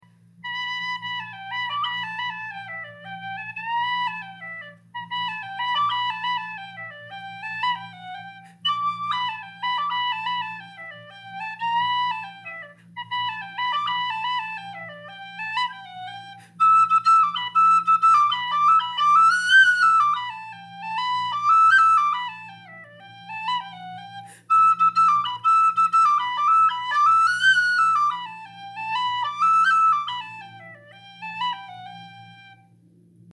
Number: #15 Key: High D Date completed: November 2023 Type: Tunable brass whistle with white plastic headpiece Volume: It is slightly louder than many MackBeths due to the larger window and its best uses would be for session, solo performance, or standard practice.